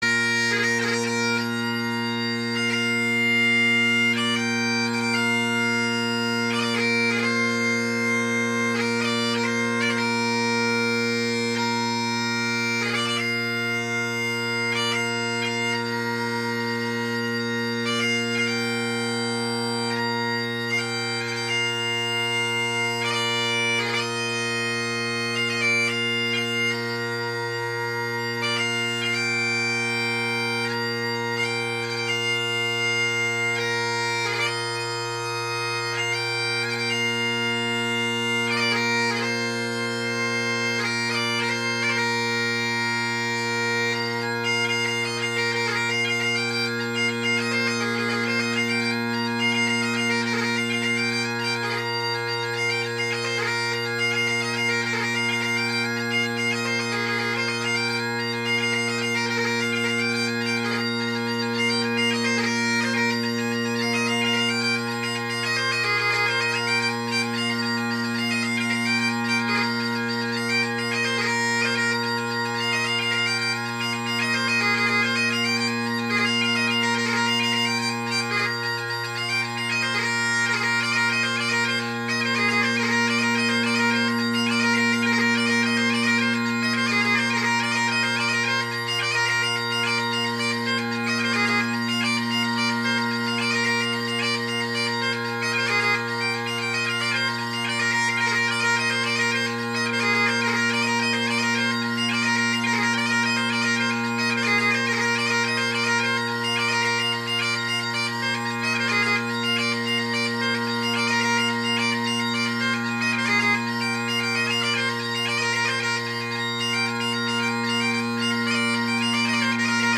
Bagpipe Sound Research, Great Highland Bagpipe Solo
Recordings taken from the end of my session with the Campbell Bb chanter with Terry pipes are below.
Fair maid + some reels (under blown high A to keep E in check) – meh audio
fairmaid_reels.mp3